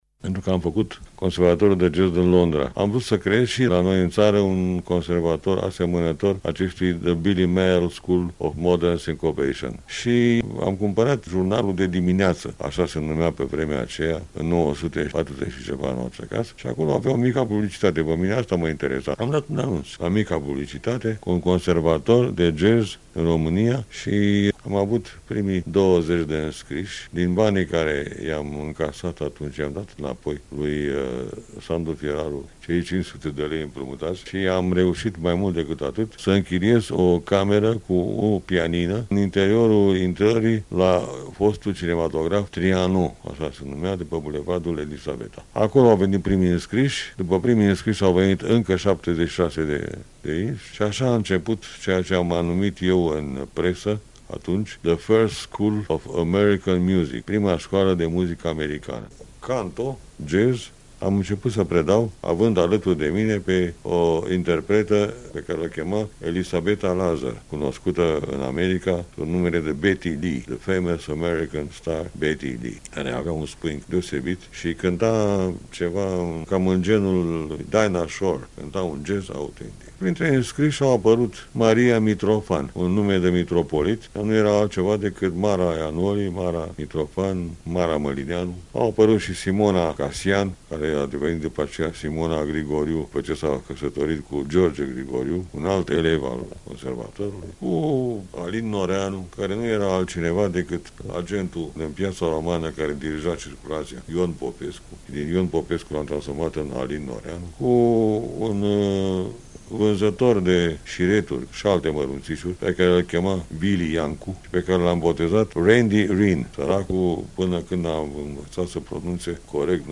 Ultimul sau interviu amplu a fost acordat Colectiei de Jazz.
Aceasta Colectie de Jazz este o minunata ocazie de a-l reasculta pe Edmond Deda vorbind despre inceputurile jazzului in Romania, de a-l auzi cantand, pian si voce, chiar la el acasa, in octombire 2002, la varsta de 82 de ani, cu acelasi farmec si umor fin, ca in perioada de maxima activitate creatoare.